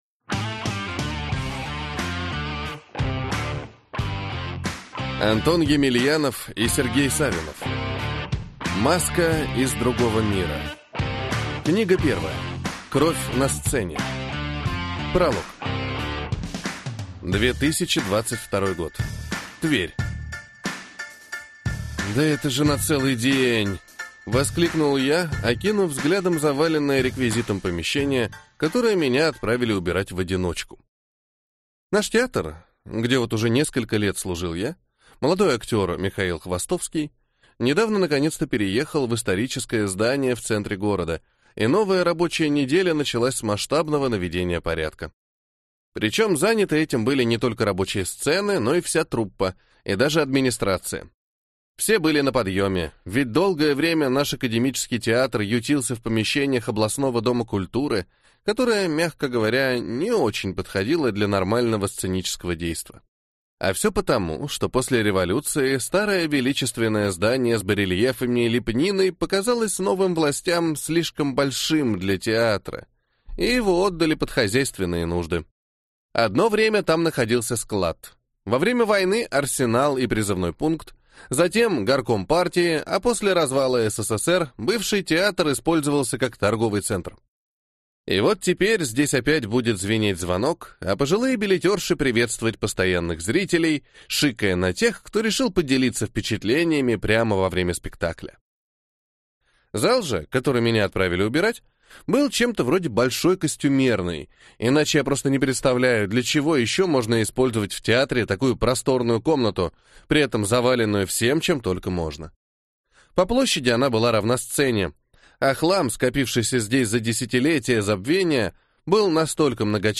Аудиокнига Маска из другого мира. Кровь на сцене | Библиотека аудиокниг